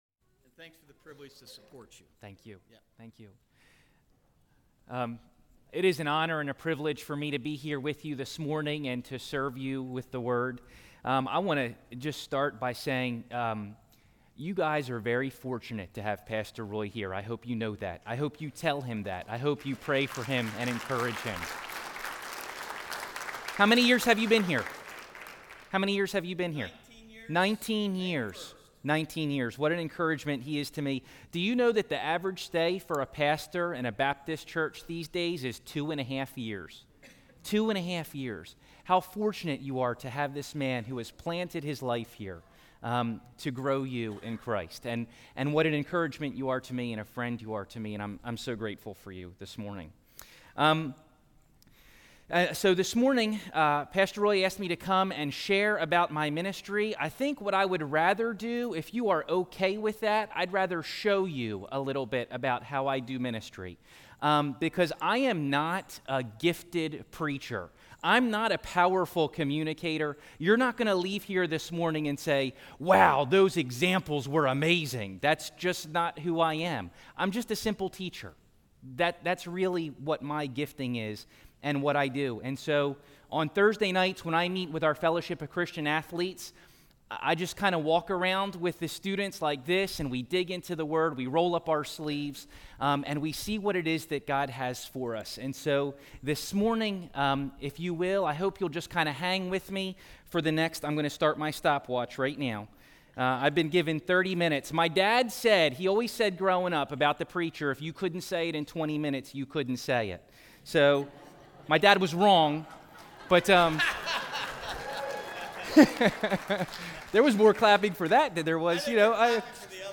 IUP-pastor-sermon.mp3